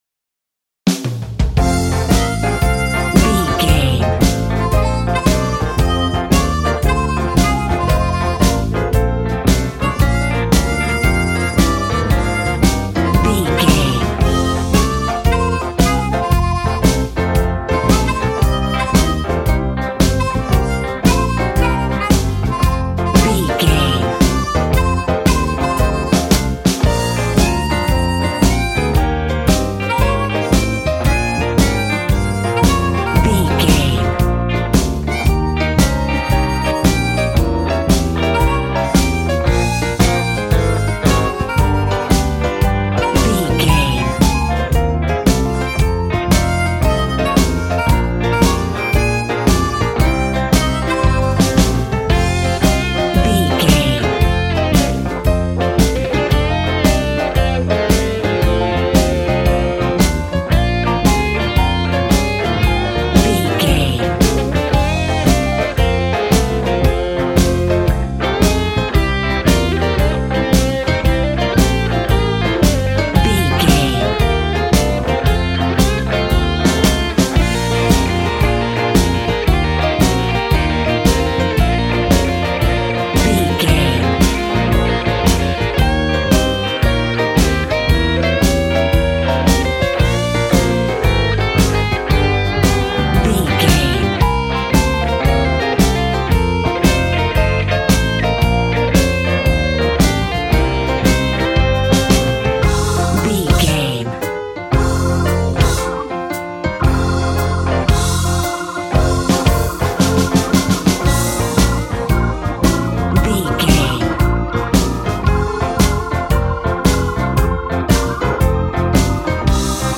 Ionian/Major
hard
bass guitar
electric guitar
electric organ
drums